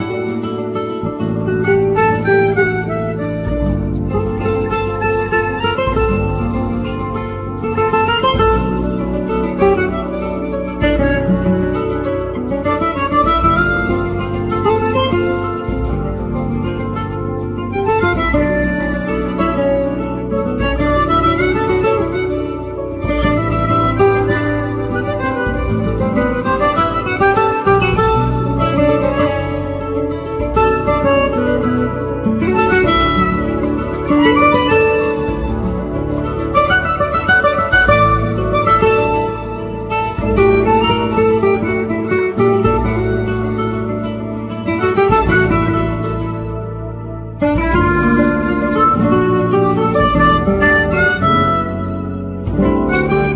Guitar,Lute
Vocals,Hand Clapping
Percussion
Keyboards
Harmonica
E-Bass
Cajon,Hand Clapping,Jaleos